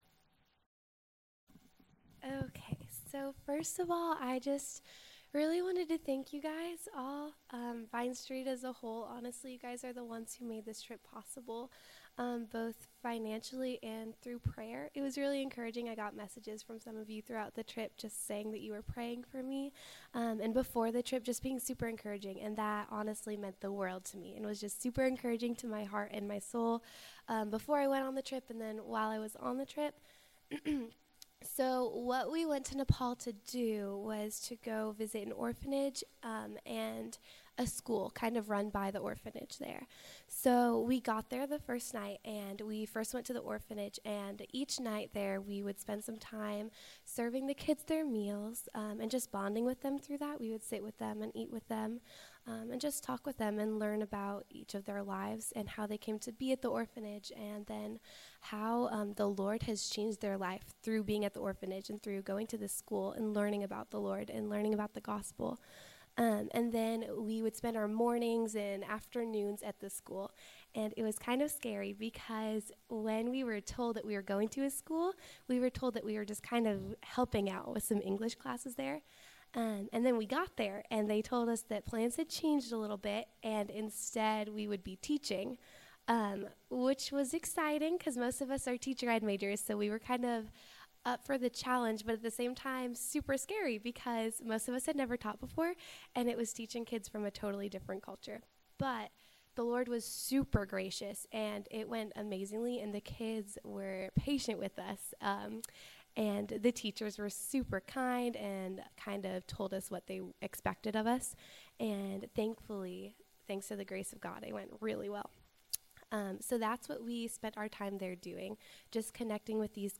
She shared about her experience during a recent Sunday Evening service.